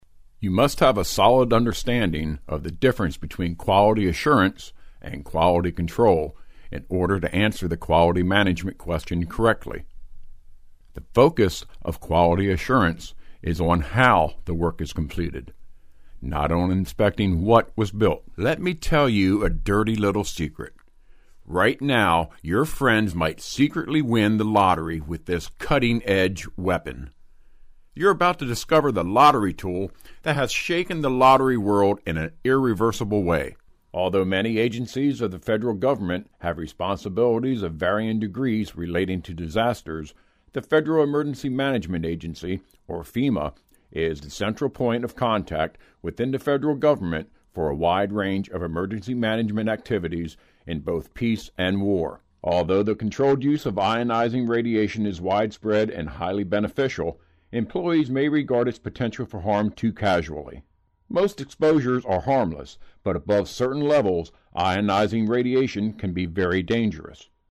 Strong yet pleasing voice, warm, humorous, sincere, professional, believable.
English speaker(us). Smooth,warm voice for narration.
Sprechprobe: eLearning (Muttersprache):